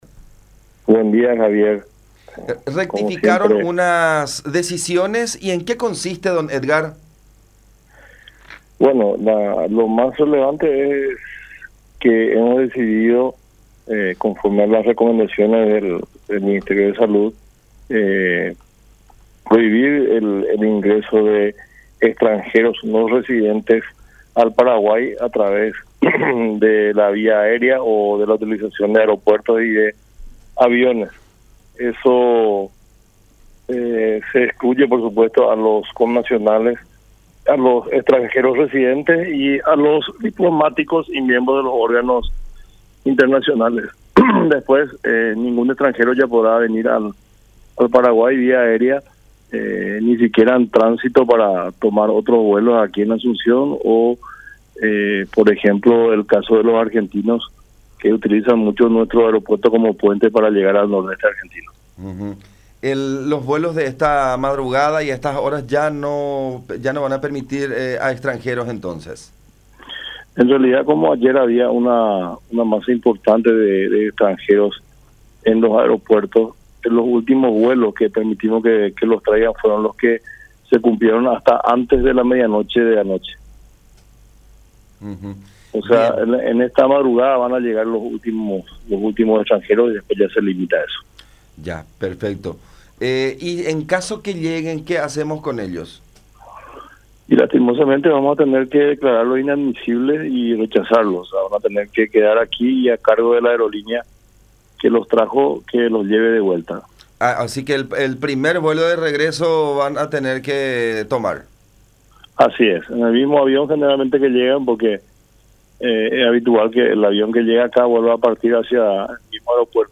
“Ni siquiera en tránsito para poder tomar otro vuelo, incluso quienes usan como escala nuestro país para trasladarse a otro territorio”, especificó Édgar Melgarejo, presidente de la DINAC, en diálogo con La Unión.